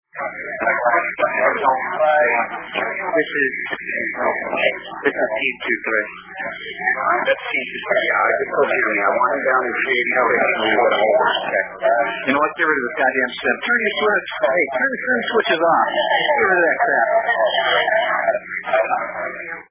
The relevant audio, with context, can be heard at this clip from Channel 2, DRM1, DAT 2, the MCC position.